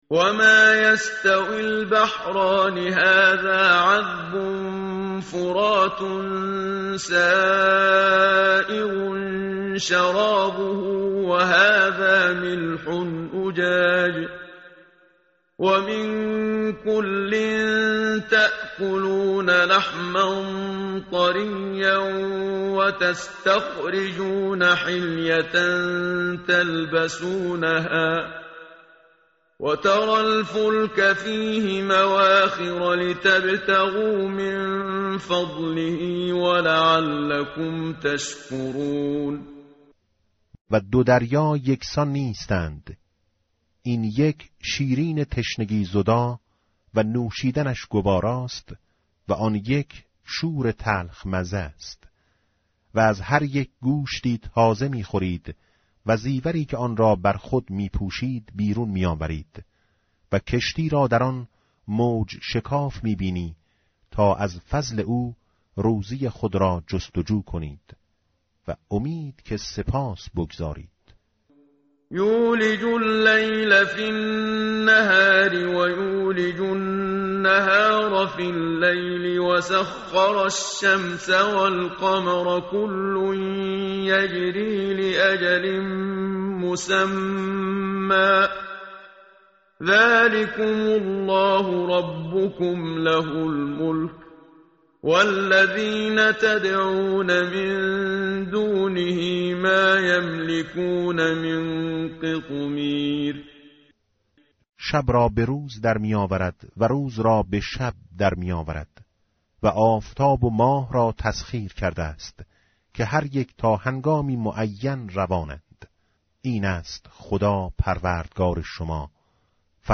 tartil_menshavi va tarjome_Page_436.mp3